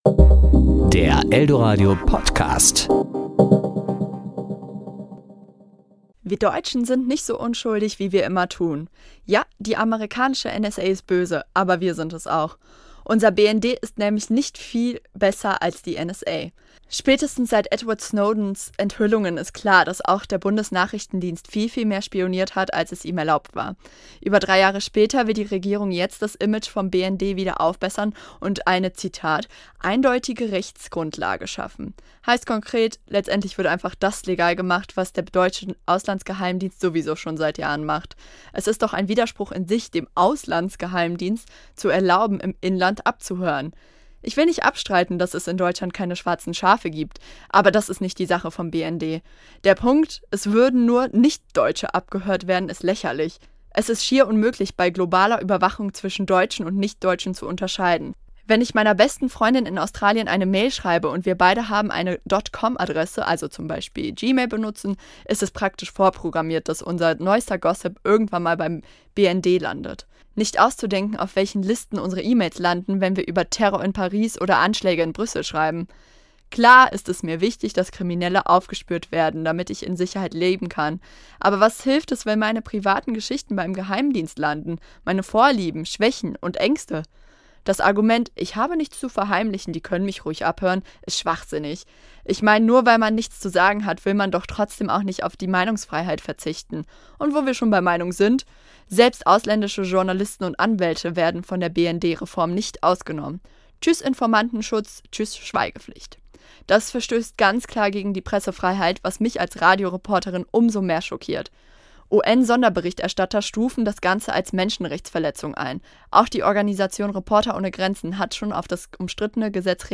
Kommentar: Bundestag berät über BND-Reform